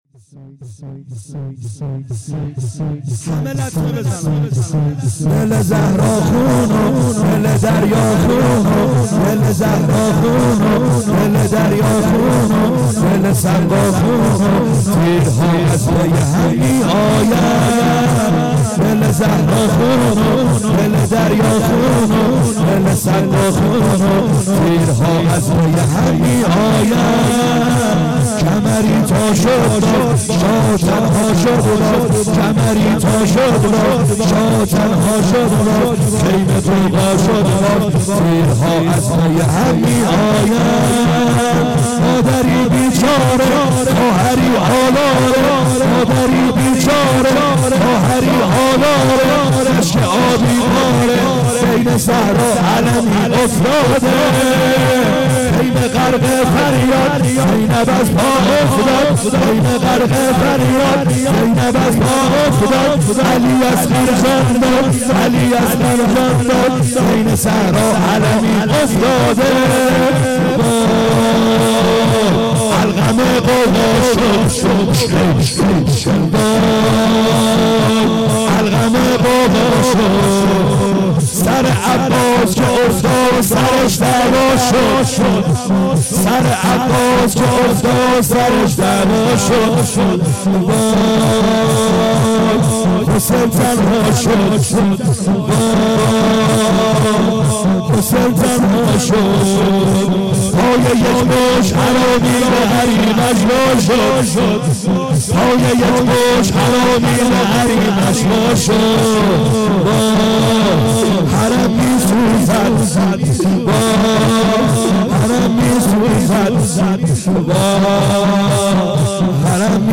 خیمه گاه - بیرق معظم محبین حضرت صاحب الزمان(عج) - لطمه زنی | دل زهرا خون